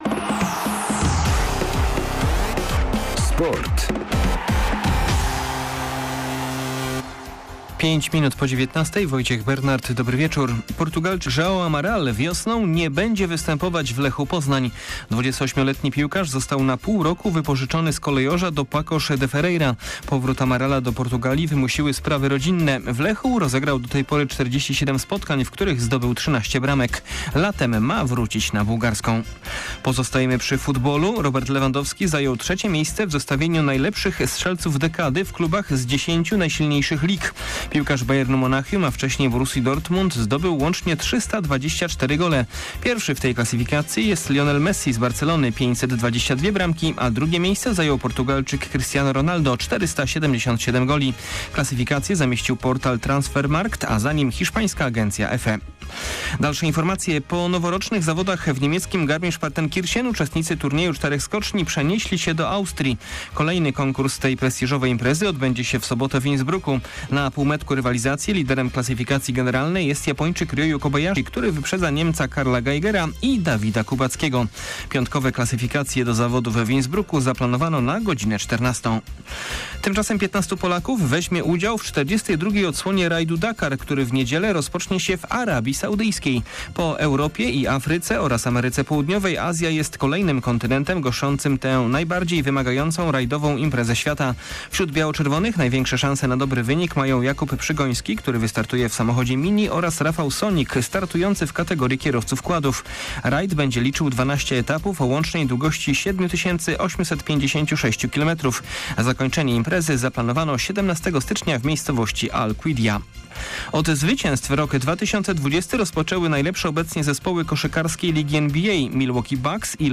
02.01. SERWIS SPORTOWY GODZ. 19:05